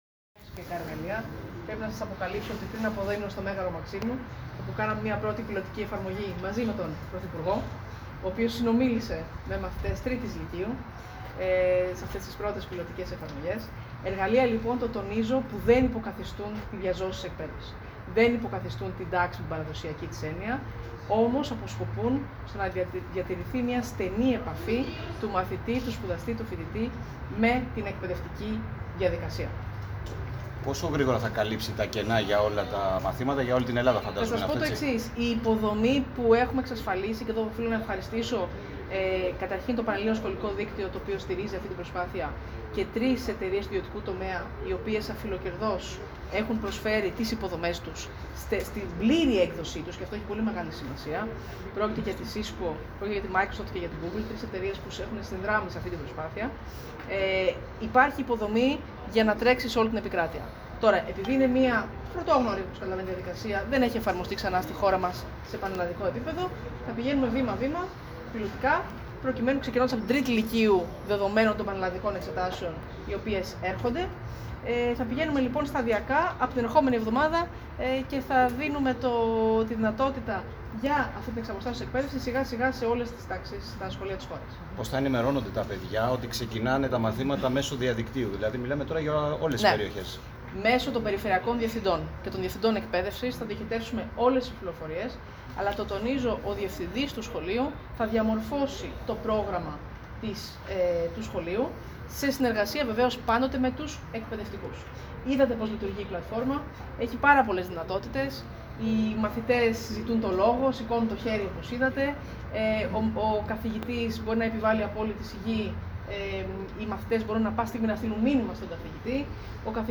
Στη συνέχεια η Υπουργός έκανε δηλώσεις στα Μέσα Μαζικής Ενημέρωσης, και ανέφερε ότι νωρίτερα η πλατφόρμα αυτή δοκιμάστηκε πιλοτικά στο Μέγαρο Μαξίμου, μαζί με τον πρωθυπουργό, ο οποίος συνομίλησε και με μαθητές της τρίτης λυκείου.